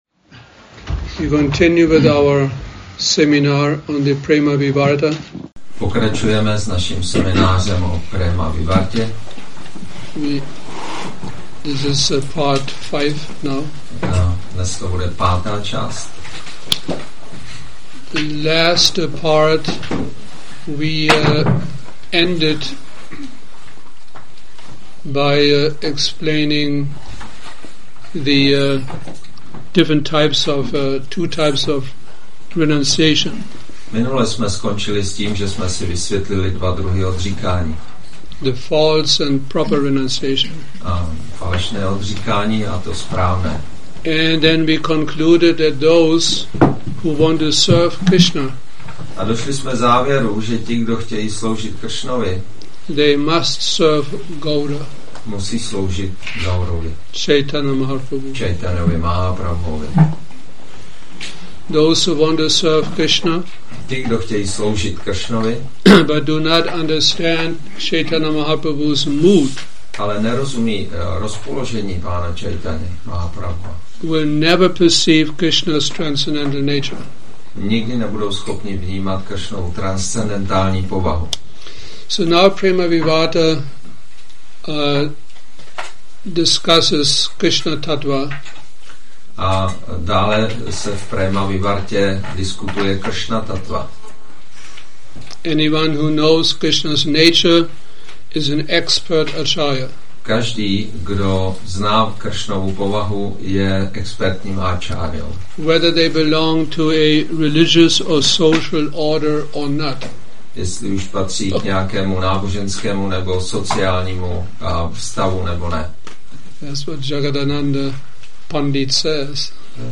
Seminář Prema Vivarta 05 – Šrí Šrí Nitái Navadvípačandra mandir